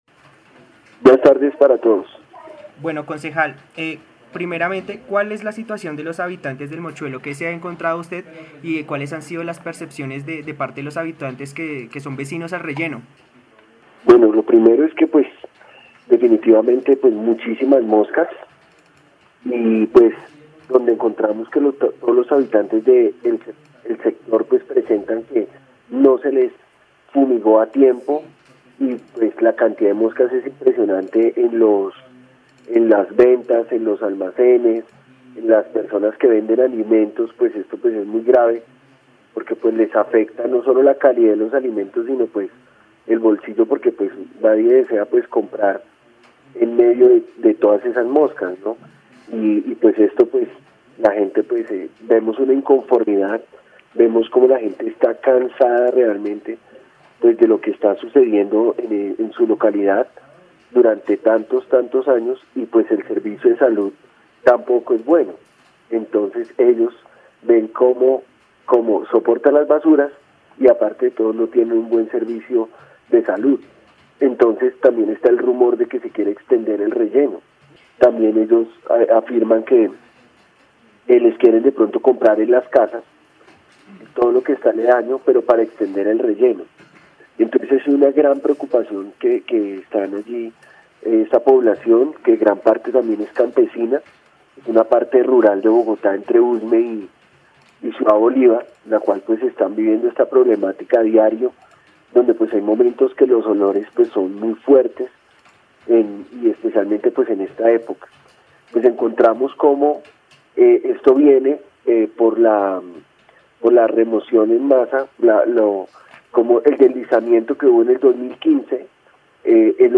En diálogo con UNIMINUTO Radio estuvo el concejal Diego Devia hablando sobre la situación actual del relleno sanitario Doña Juana en Bogotá.